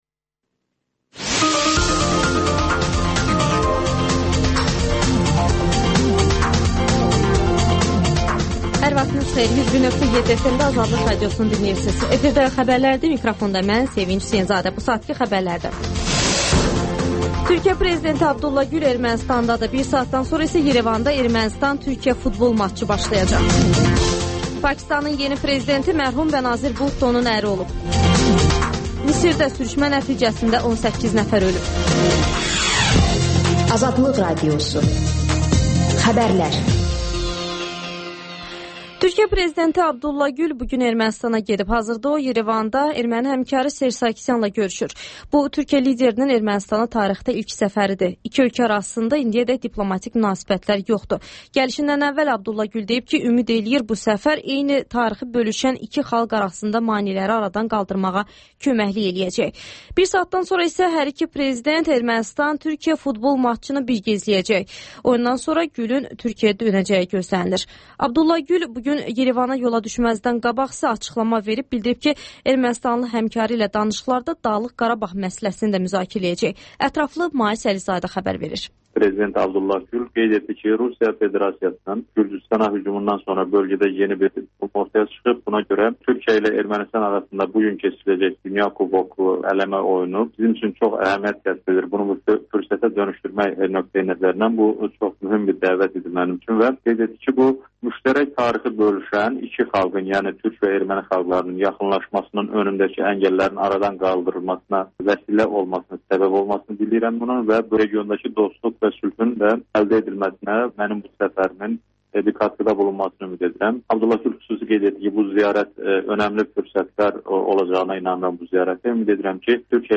Xəbərlər